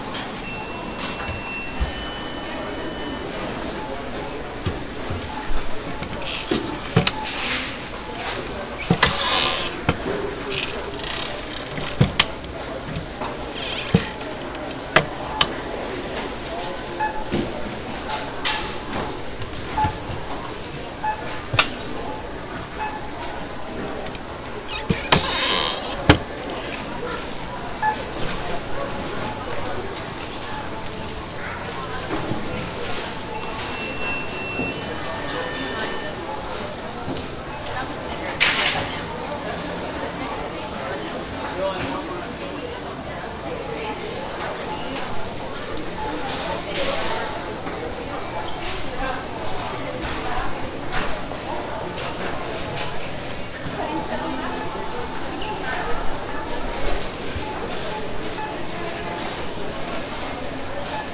描述：超市环境
Tag: BIP 语音机 超市 球童